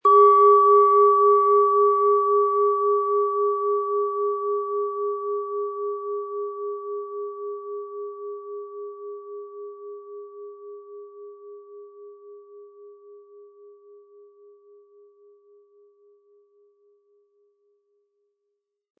Planetenton 1
Planetenschale®
Lieferung inklusive passendem Klöppel, der gut zur Klangschale passt und diese sehr schön und wohlklingend ertönen lässt.